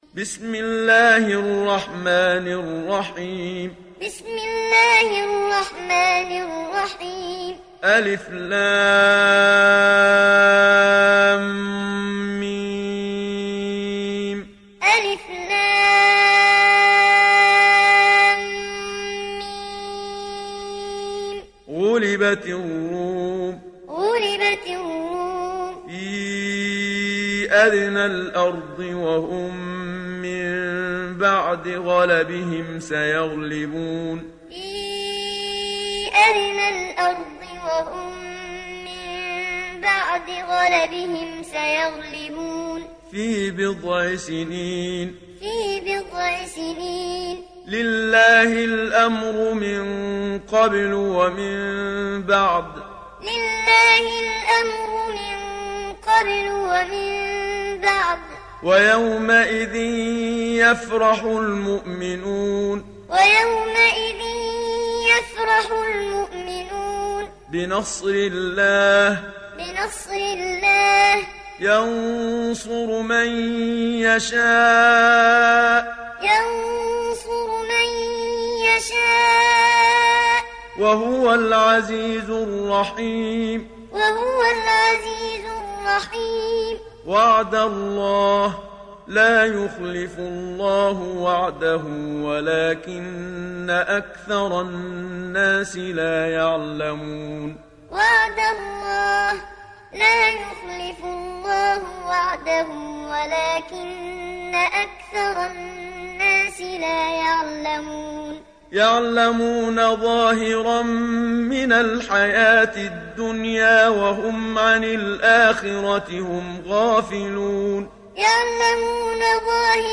دانلود سوره الروم محمد صديق المنشاوي معلم